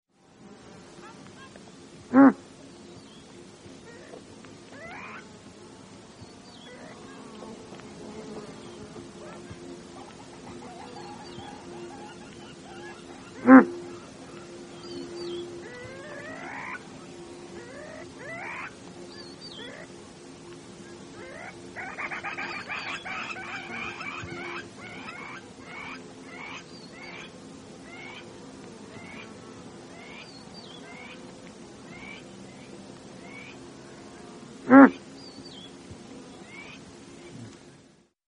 Звук газели Гранта из Кении: тревожное фырканье самца